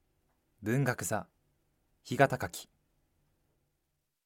ボイスサンプルはこちら↓ 名前